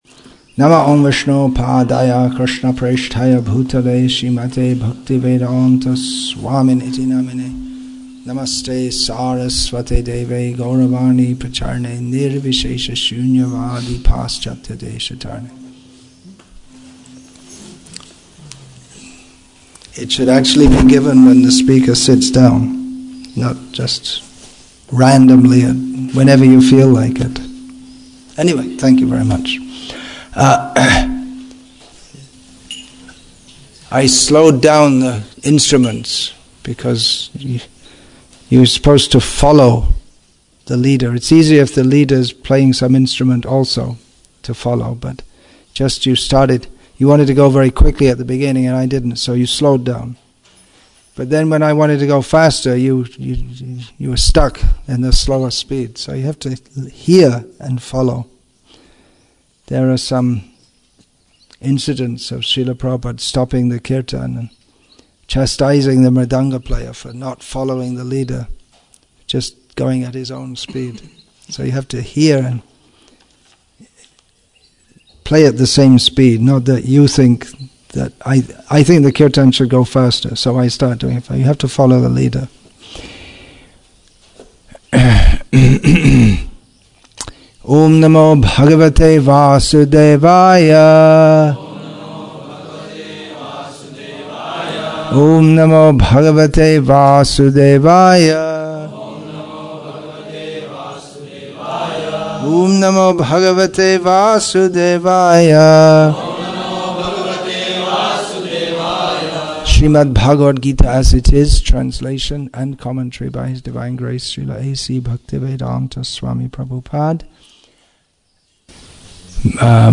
Basic Directions For Practical Spiritual Life March 15, 2020 Bhagavad-gītā English with தமிழ் (Tamil) Translation; Vellore,Tamil Nadu , India Bhagavad-gītā 8.19 1 h 28 s 58.06 MB Download Play Add To Playlist Download